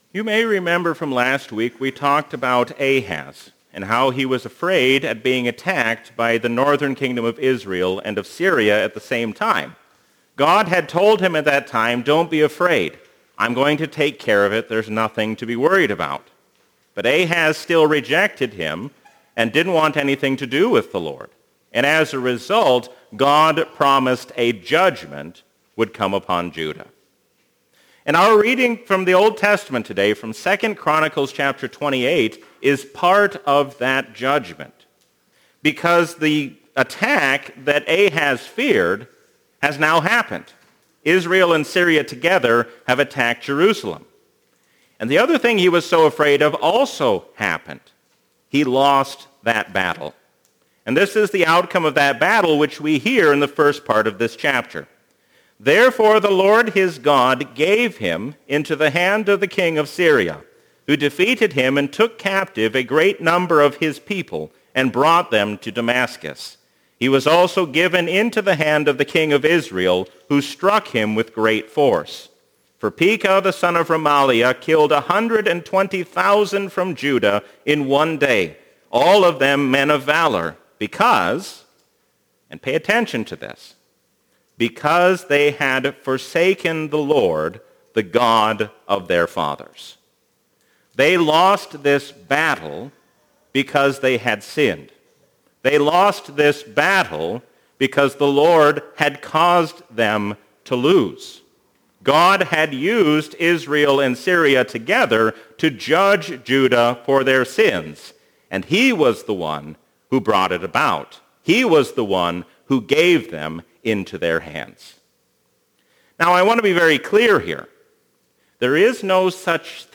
A sermon from the season "Epiphany 2022." Do not lose heart, because God is the Master of all our ways.